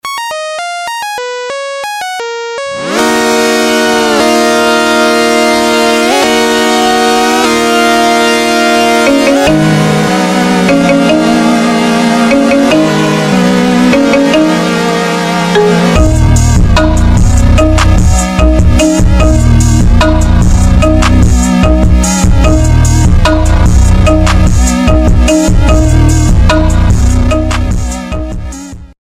Рингтоны без слов , Рингтоны ремиксы
громкие рингтоны , Маримба